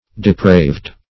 Depraved (d[-e]*pr[=a]vd"); p. pr. & vb. n. Depraving.]